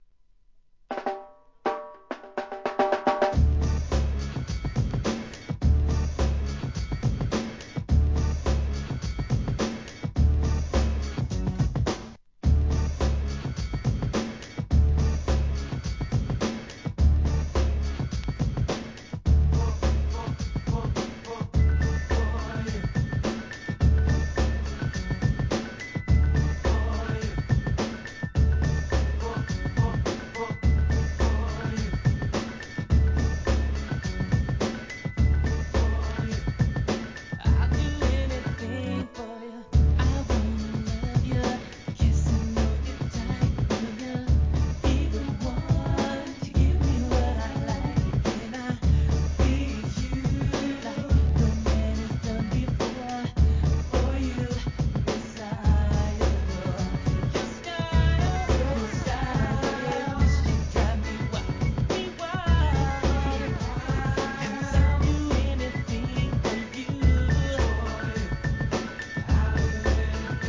HIP HOP/R&B
1993年、NEW JACK SWING調のダンサブル・ナンバー!!